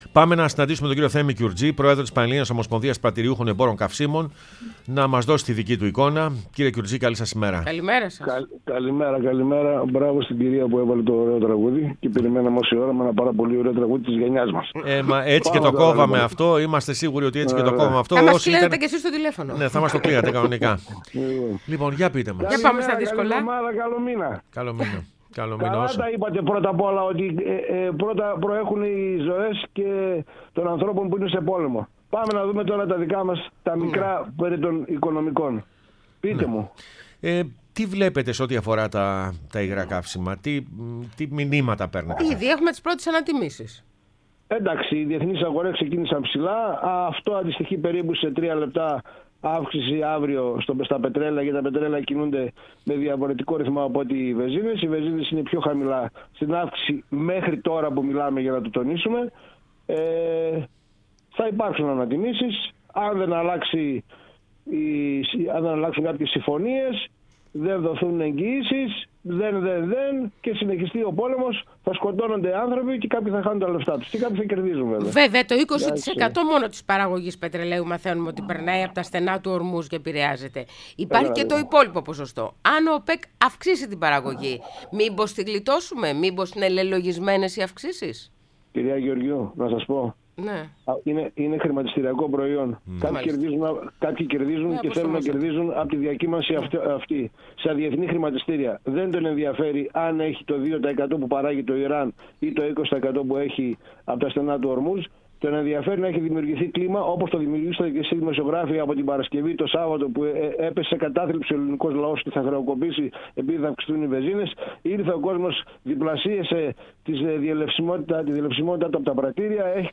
μίλησε στην εκπομπή «Πρωινές Διαδρομές»